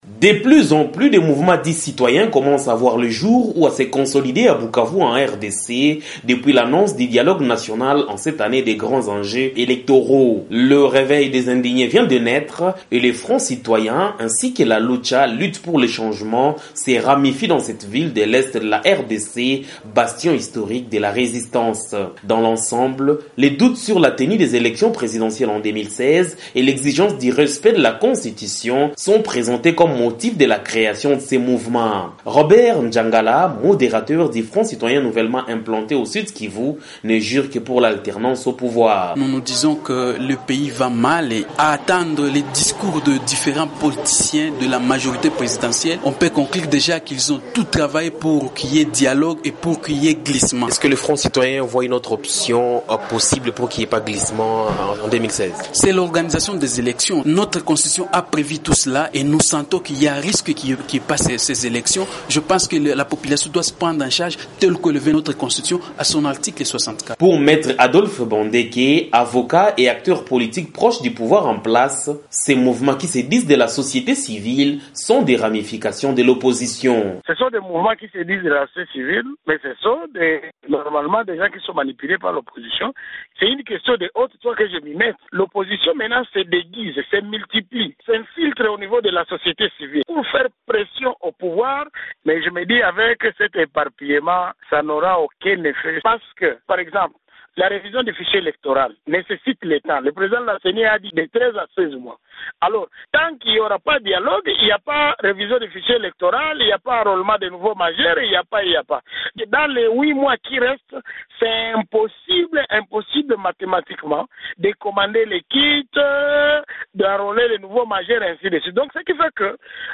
Des leaders de la societe civiles et un avocat du PPRD